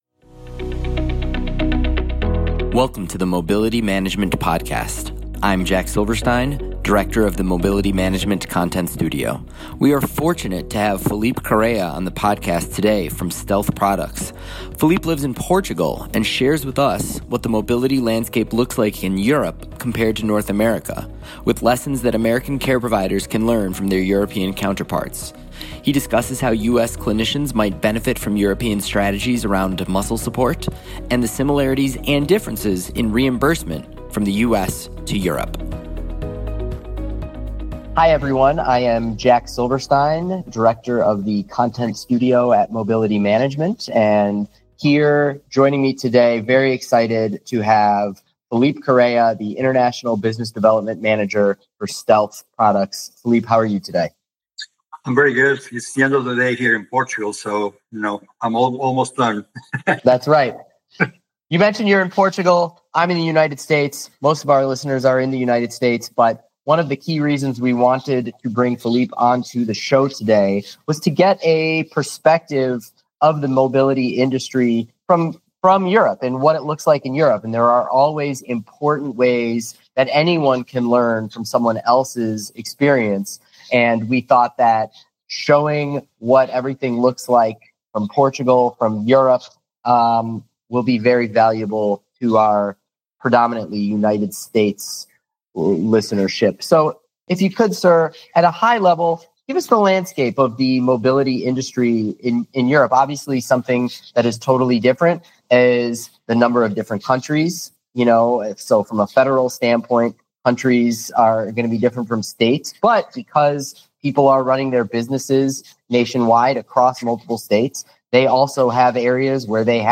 Mobility Management Podcast What U.S. Clinicians Can Learn from European Seating: interview